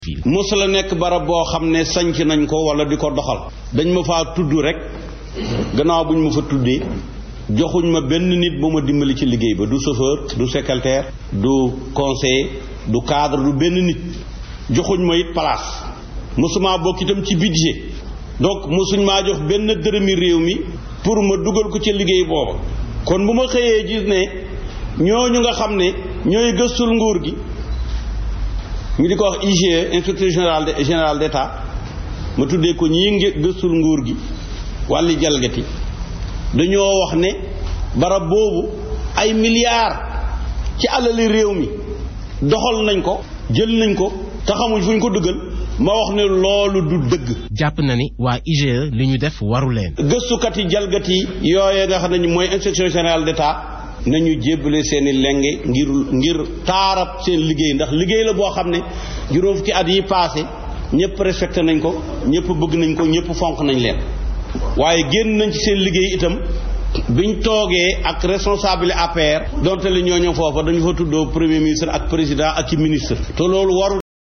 Le président du Front des alliances patriotiques (FAP), Ahmed Khalifa Niasse, a fustigé la décision de l’Inspection générale d’Etat (IGE) d’avoir cité la Mission de promotion de la nouvelle capitale politique et administrative parmi les 59 entités étatiques à supprimer, affirmant que cette structure ’’n’a jamais existé’’. ‘’Ils ont déclaré que la mission aurait dépensé inutilement des milliards et donc ciblée particulièrement parmi les 59’’, a indiqué M. Niasse, ancien chef de cette mission, au cours d’un point de presse, jeudi à Dakar.